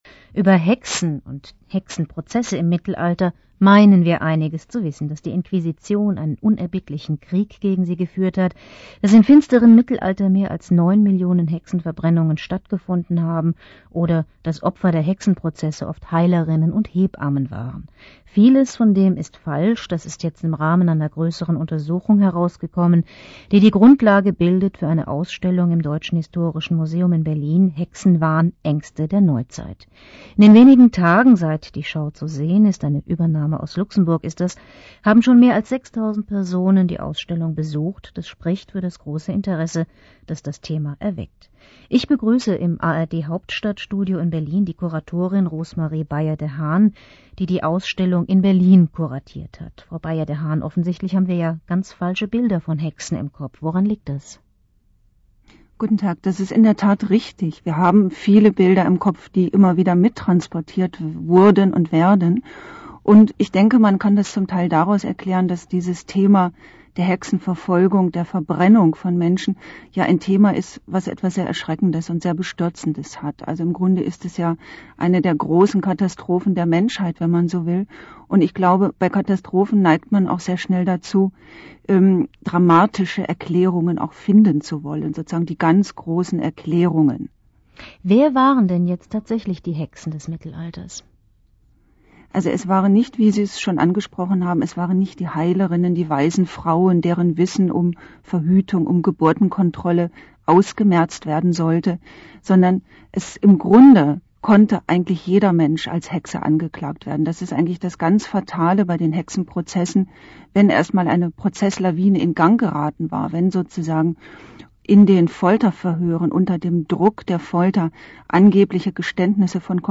Forum